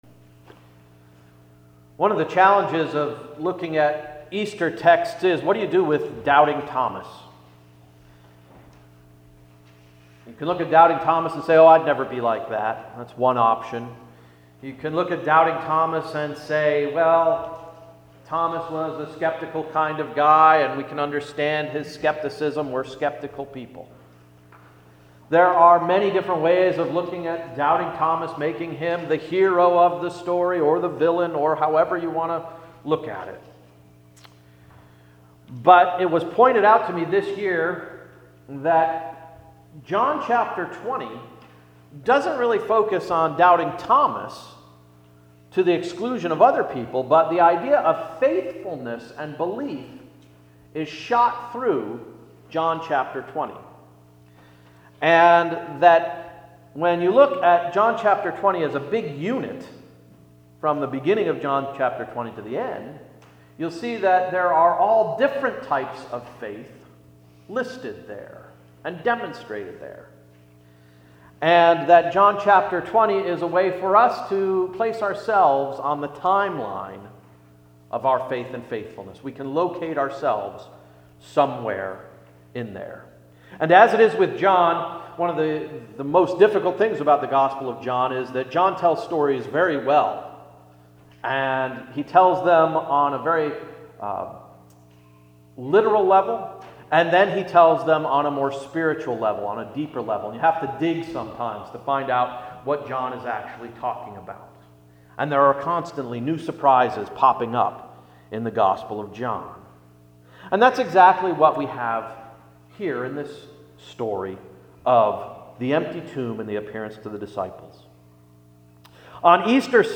Sermon of April 12–John 20:19-31–“What’s Next?”